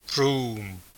Scottish Gaelic name